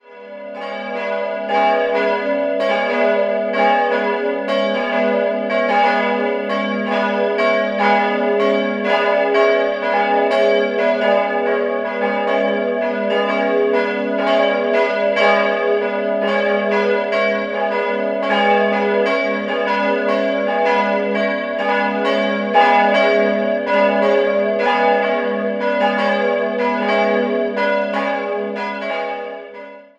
3-stimmiges TeDeum-Geläut: gis'-h'-cis'' Die Glocken wurden 1951 vom Bochumer Verein für Gussstahlfabrikation gegossen.